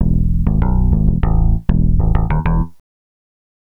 Swinging 60s 4 Bass-E.wav